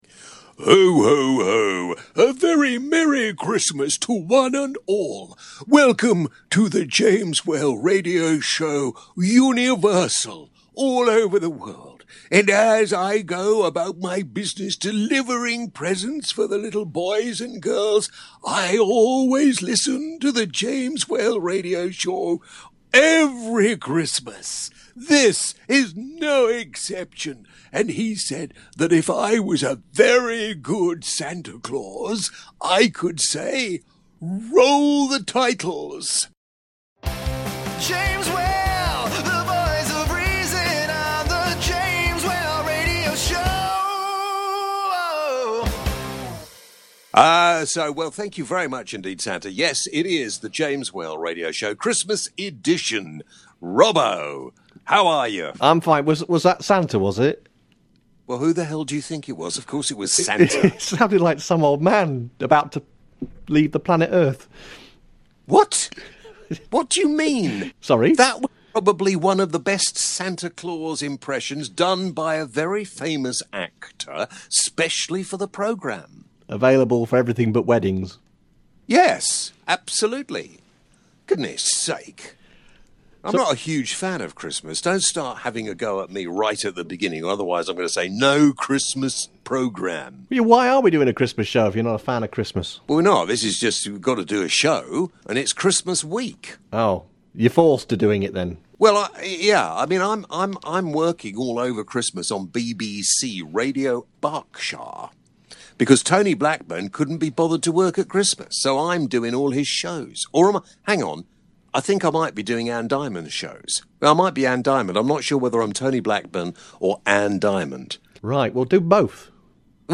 James Sings his Christmas song!, ...